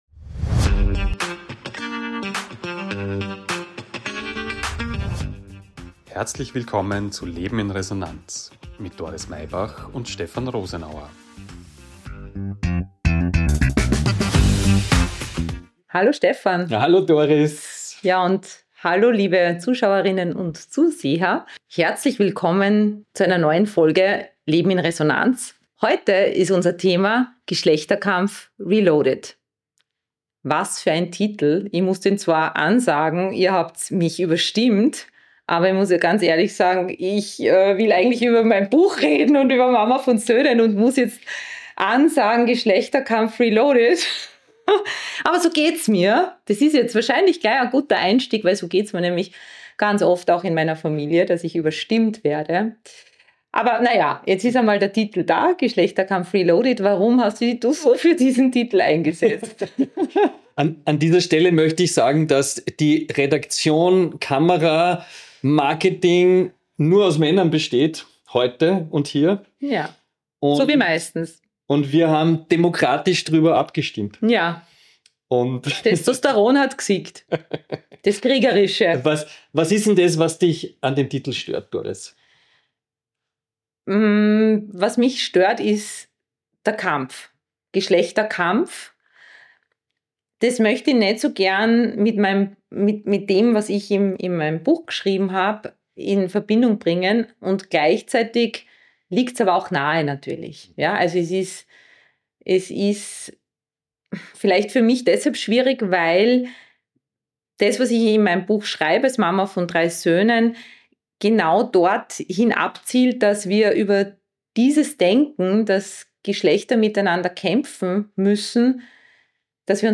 Ein ehrliches, manchmal berührendes, oft augenzwinkerndes Gespräch über Rollenbilder, weibliche Identität und das Leben als Mutter zwischen Verantwortung und Selbstentfaltung.